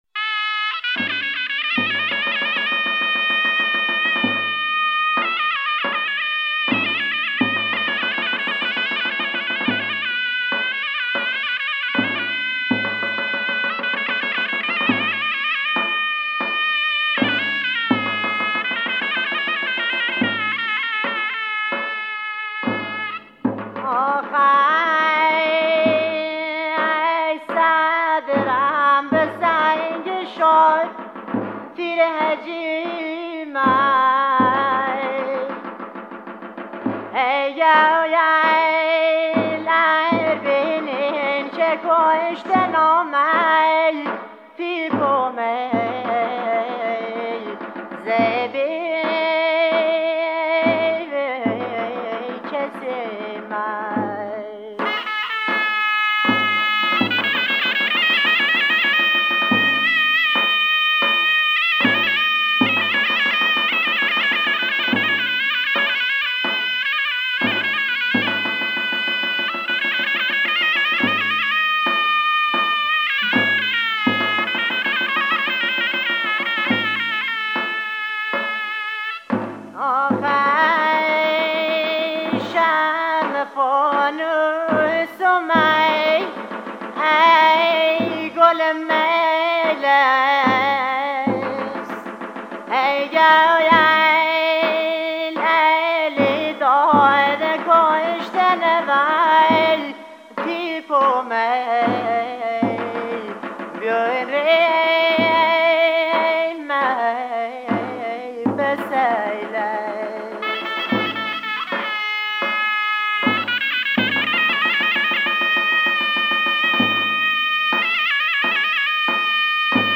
اصطلاح «گاگریو» به معنی گاه یا زمان گریستن، آوازهای متنوعی را شامل می شود كه هر یك در گوشه ای از مناطق بختیاری نشین شكل گرفته اند و امروزه به همه بختیاری ها تعلق دارند.
آنان با مویه گری های انفرادی و اغلب جمعی، فضای تأثرانگیز و تسكین دهنده ای را ایجاد می كنند.